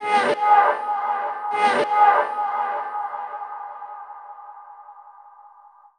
Ambient
1 channel